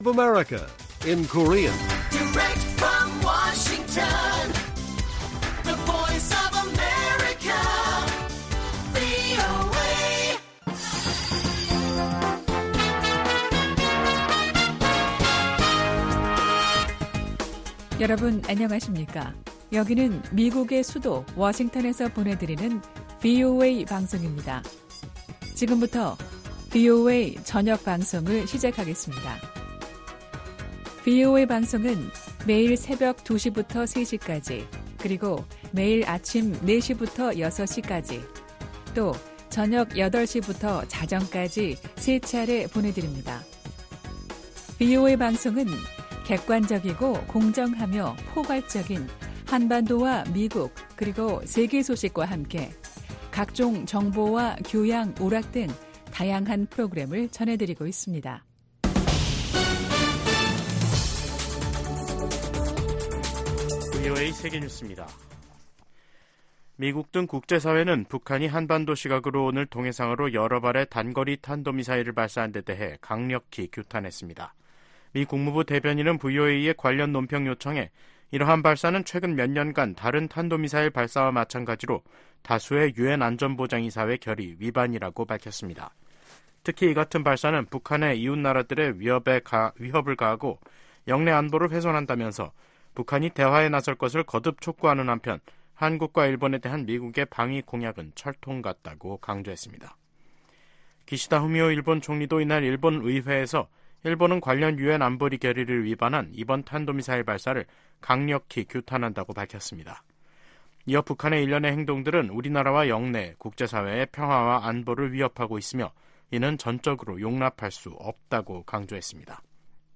VOA 한국어 간판 뉴스 프로그램 '뉴스 투데이', 2024년 3월 18일 1부 방송입니다.